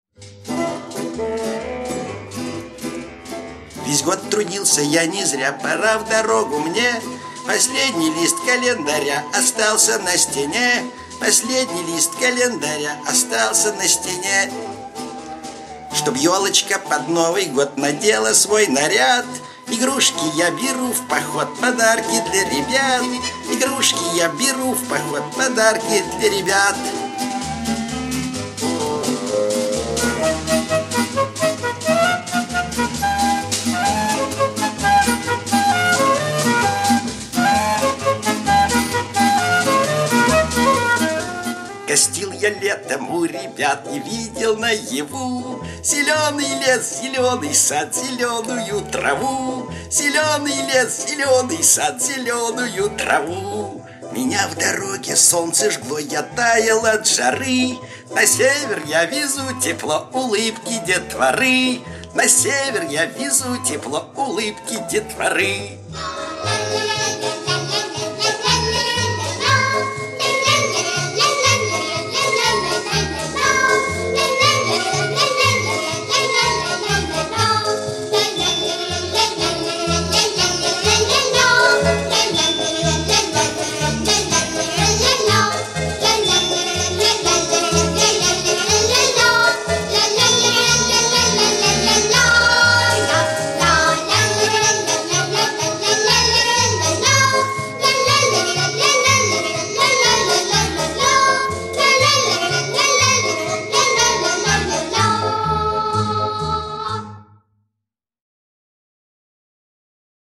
новогодняя композиция